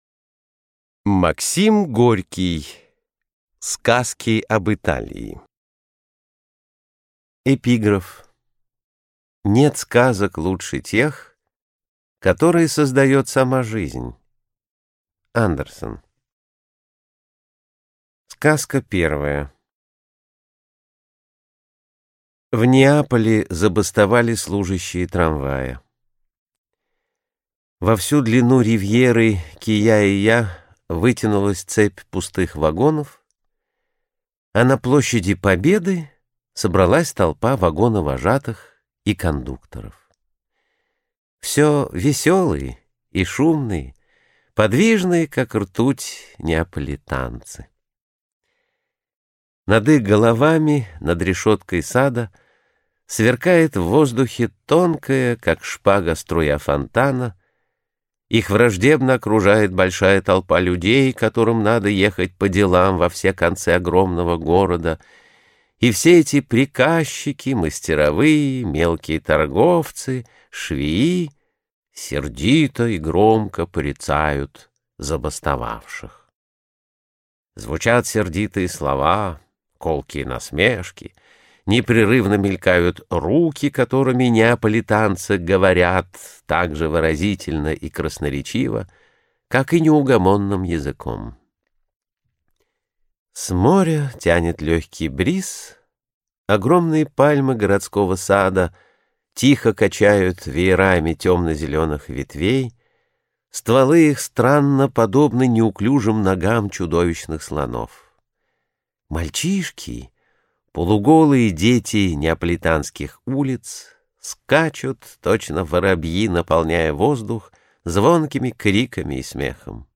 Прослушать фрагмент аудиокниги Сказки об Италии Максим Горький Рассказы Повести Классическая проза Произведений: 16 Скачать бесплатно книгу Скачать в MP3 Вы скачиваете фрагмент книги, предоставленный издательством